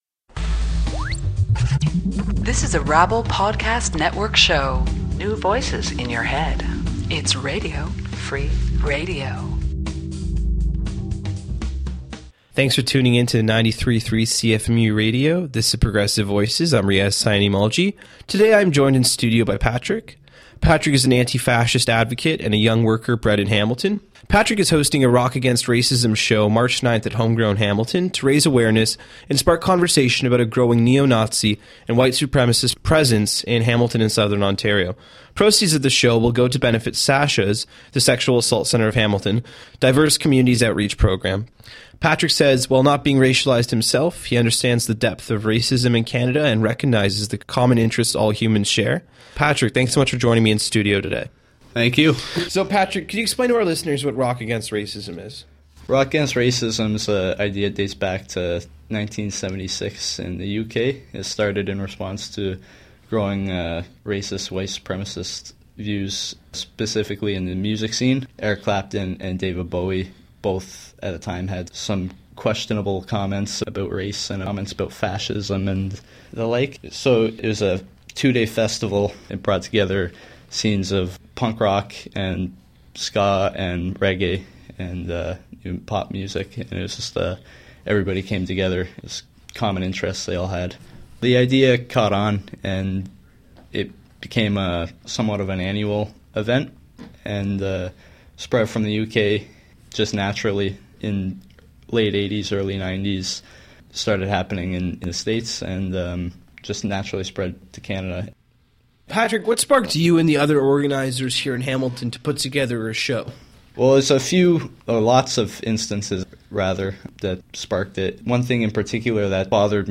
rock_against_racism_interview.mp3